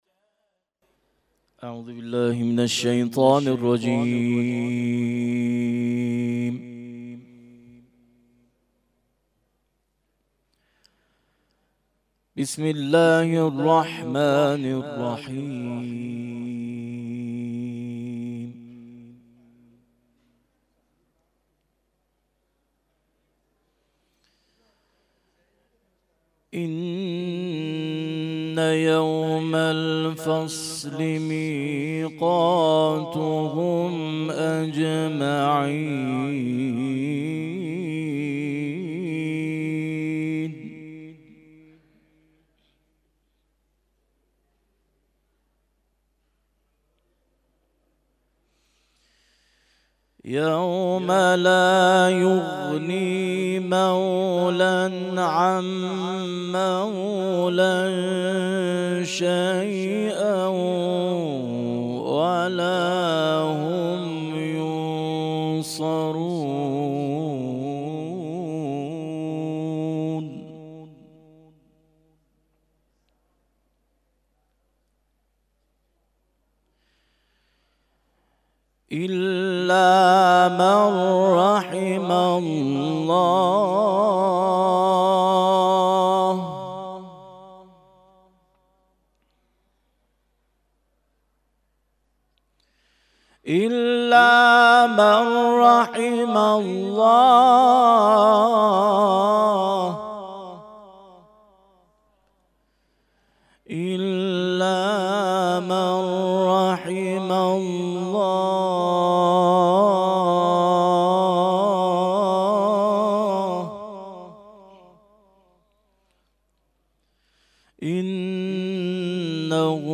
تلاوت قرآن کریم